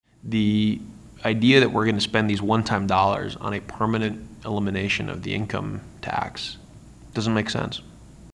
Senate Democratic Leader Zach Wahls of Coralville says the state’s flush with cash because of federal pandemic relief.